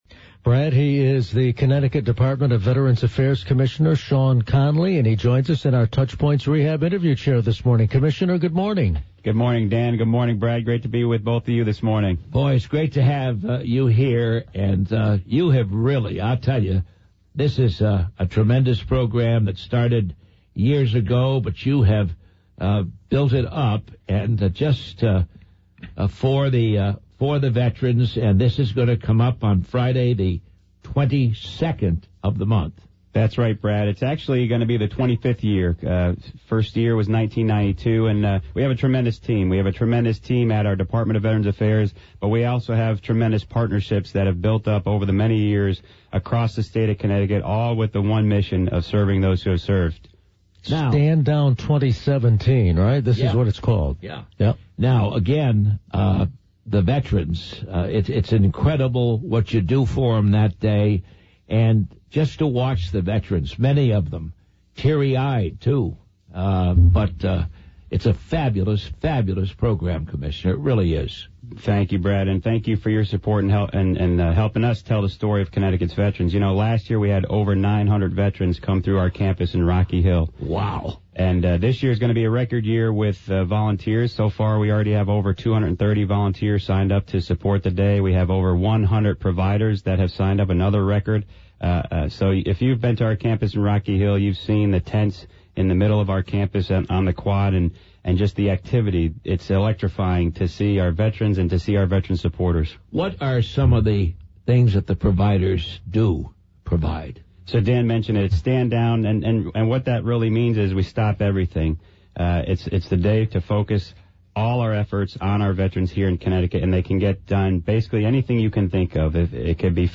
Everything is in place for Stand Down 2017 at the Connecticut Department of Veterans Affairs in Rocky Hill. In this interview, CT Veterans Affairs Commissioner Sean Connolly talks about the Sep. 22 event, which runs from 8 a.m. to 2 p.m.Podcast photo credit: Getty Images Plus/...